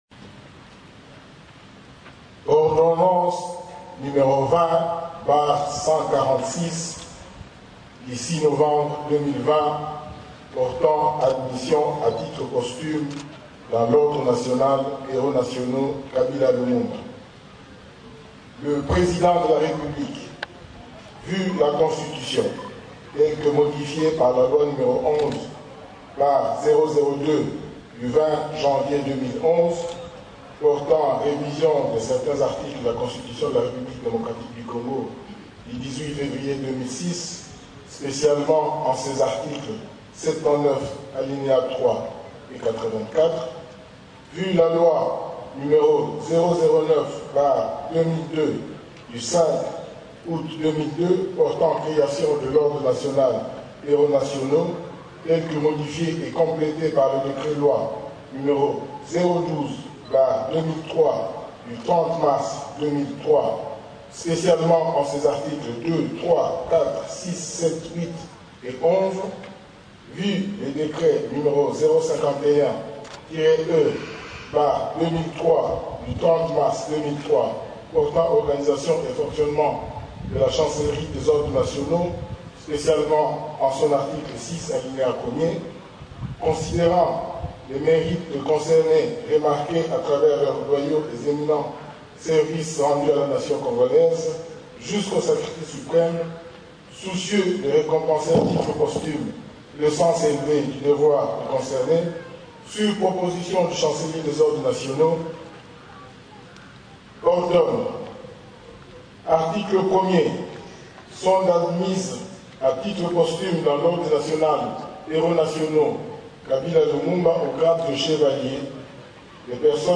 Des plus amples détails dans cette déclaration du général Matutezulua André, chancelier des ordres nationaux.